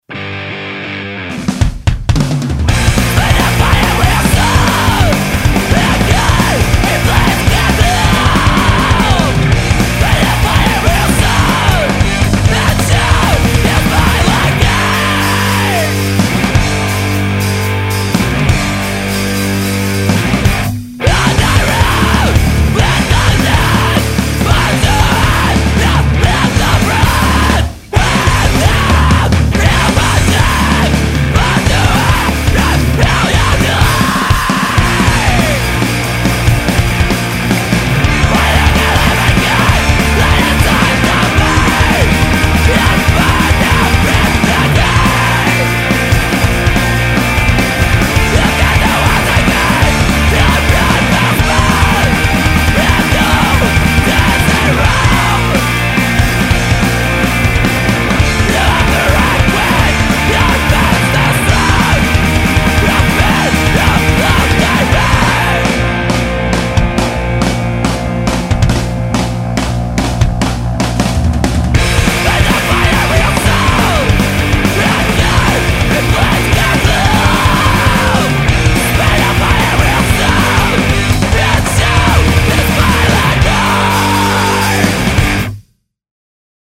Estilo: Metal / Hardcore